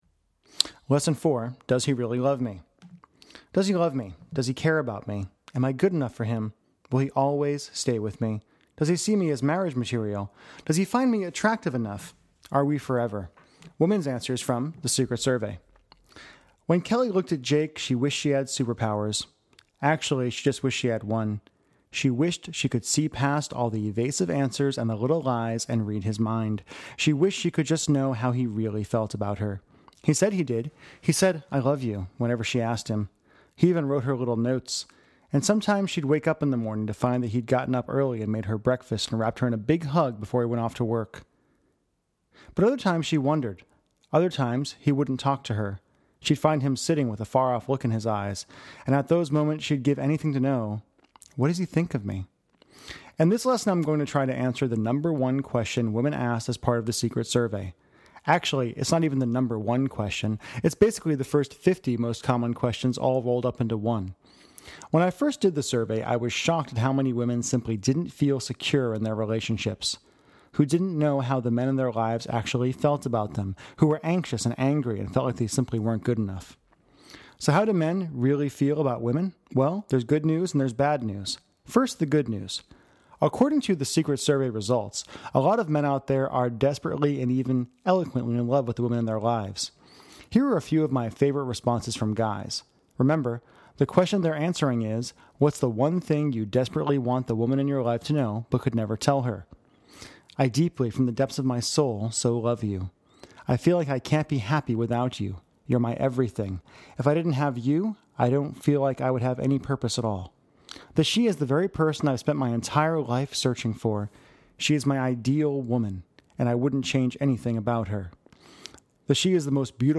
Secret+Survey+Lesson+4+Does+He+Really+Love+Me.mp3